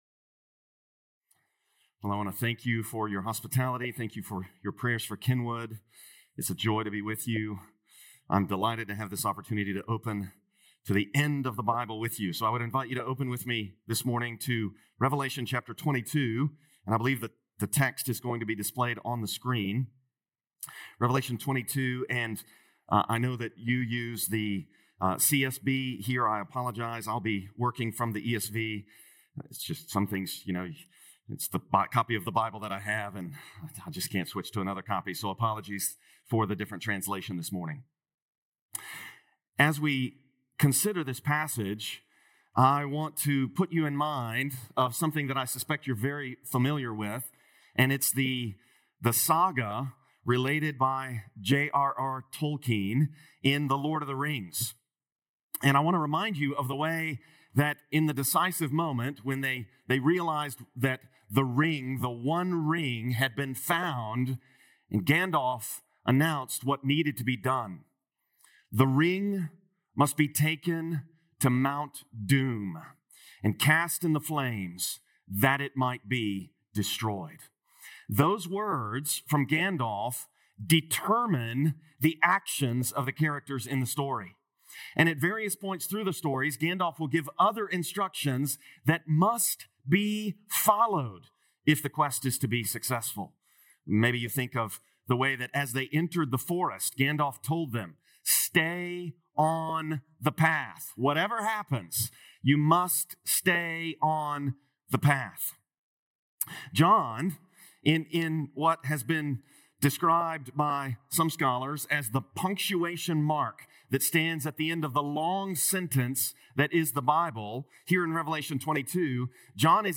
Nov 24th Sermon | Revelation 22:6-21